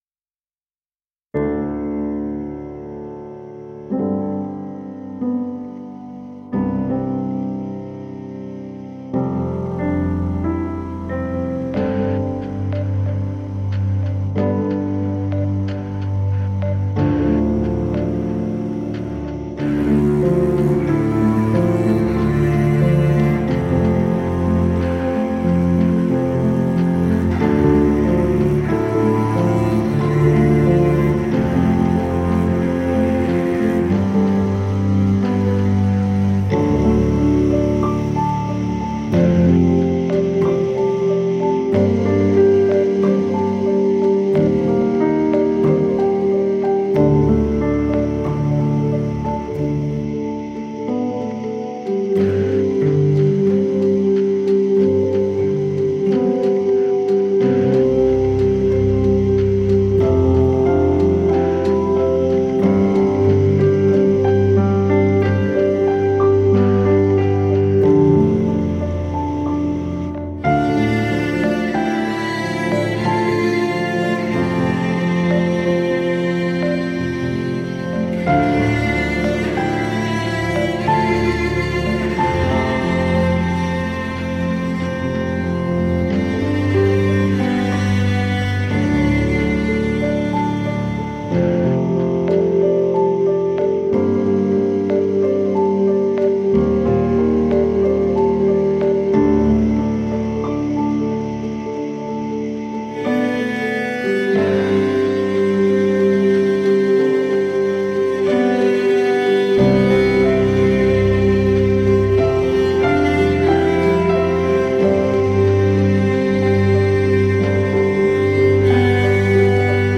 unique merger of electronics, electroacoustics and acoustics